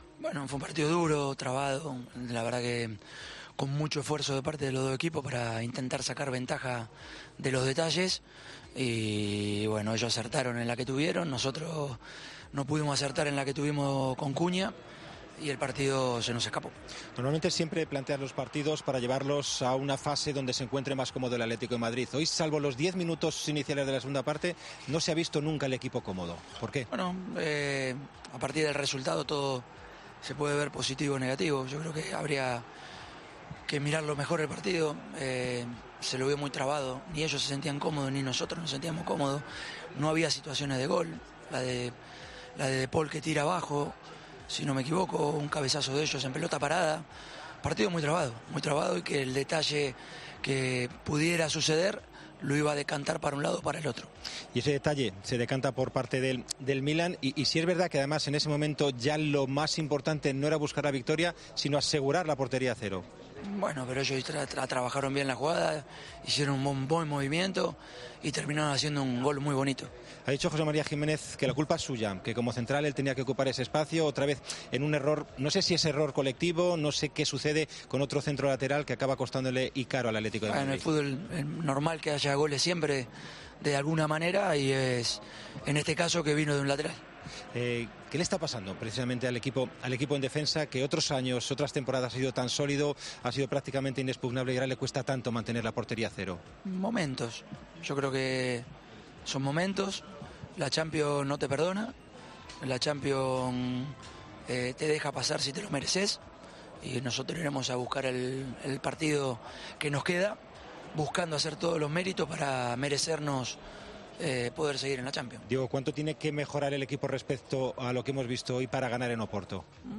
AUDIO: El entrenador rojiblanco habló de la derrota del equipo ante el Milan que obliga a los del Cholo a ganar en Oporto y esperar al resultado del Milan...